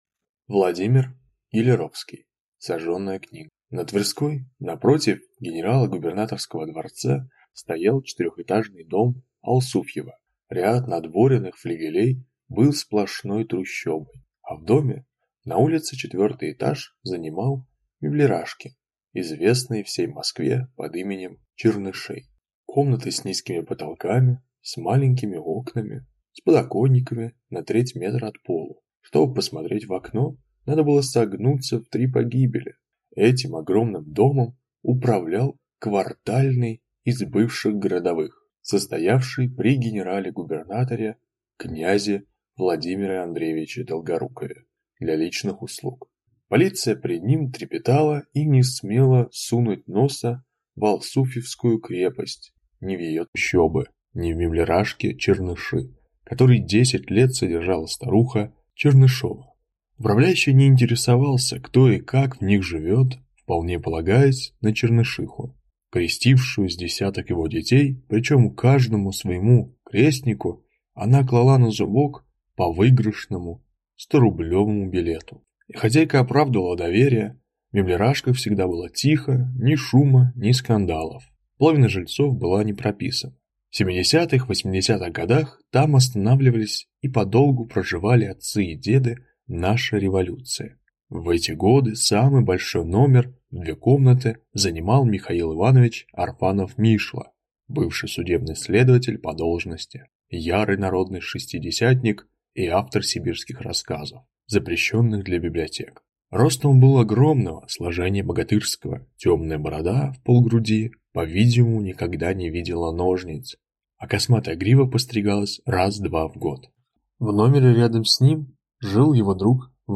Аудиокнига Сожженная книга | Библиотека аудиокниг